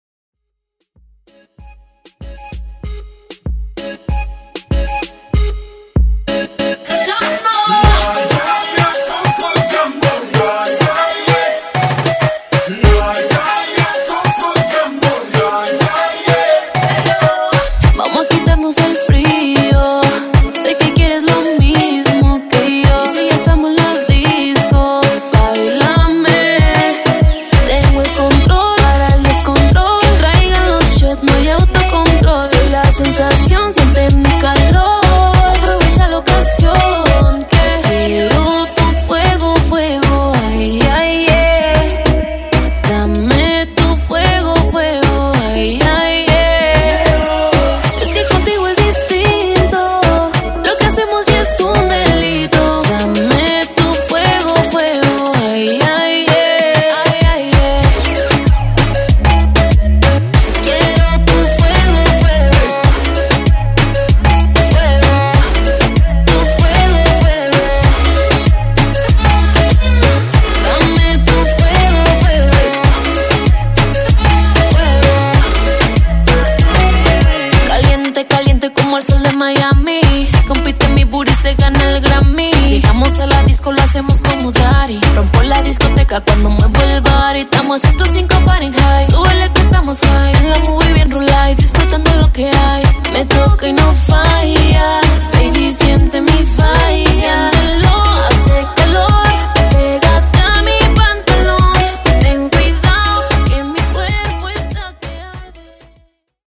Genre: COUNTRY
Clean BPM: 86 Time